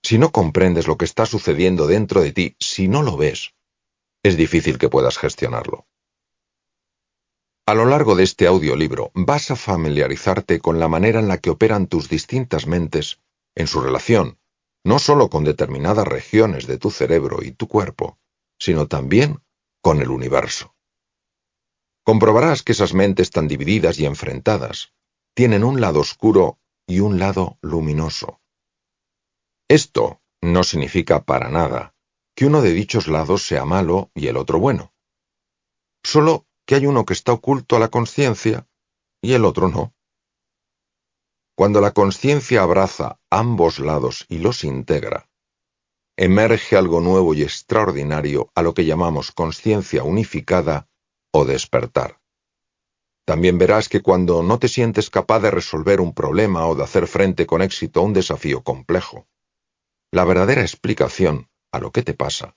audiolibro Resetea tu mente Descubre de lo que eres capaz Mario Alonso